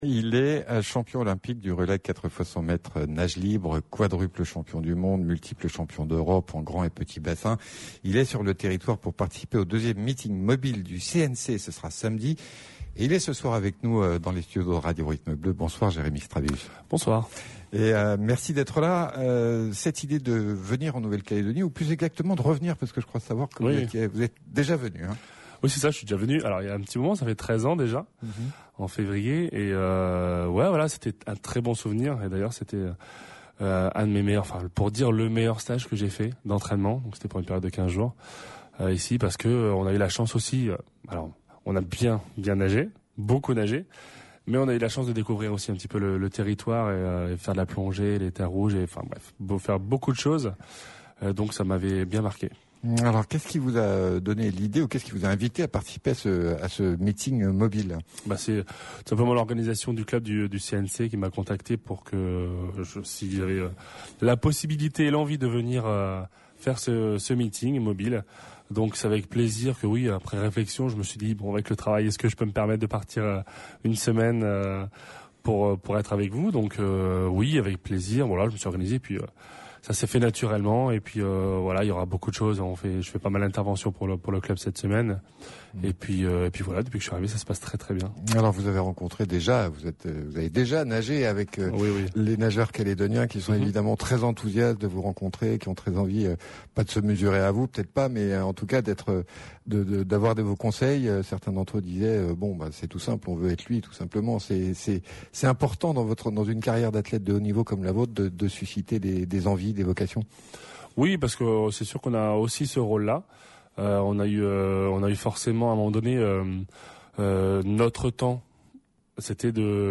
Jérémy Stravius, invité exceptionnel du journal de 17h45. Le nageur champion olympique du relais 4x100m nage libre, quadruple champion du monde et multiple champion d’Europe en grand et petit bassin est sur le Territoire pour participer au 2ème meeting Mobil du CNC samedi. 3 ans après la fin de sa carrière il se jettera à nouveau à l’eau pour son plus grand plaisir.